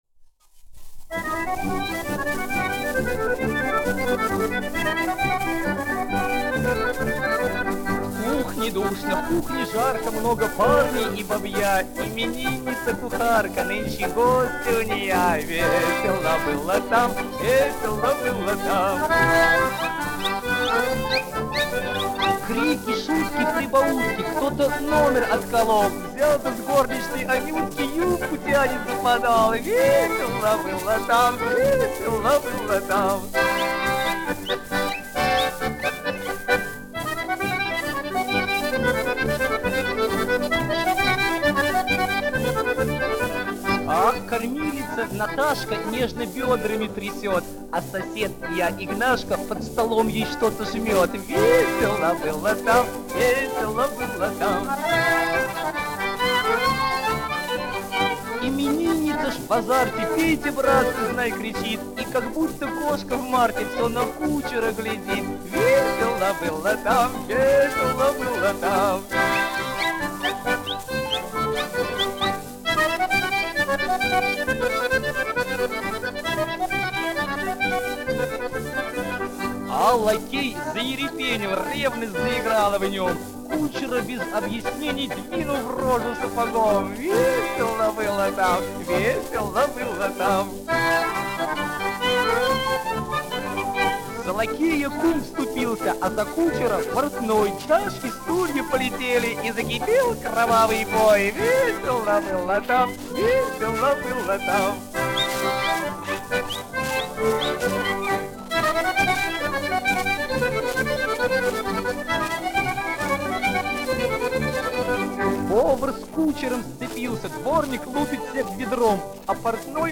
1 skpl. : analogs, 78 apgr/min, mono ; 25 cm
Častuškas
Krievu tautasdziesmas